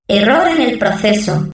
voz nș 0138